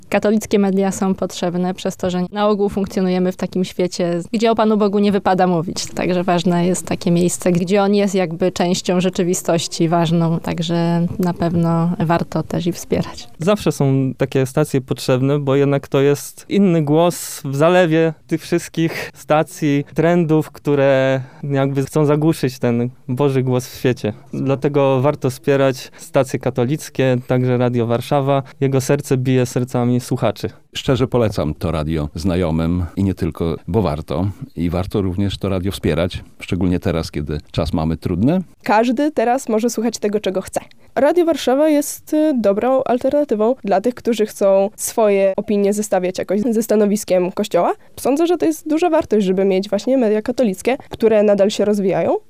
Nasi darczyńcy regularnie wspierają Radio Warszawa i zachęcają do tego innych: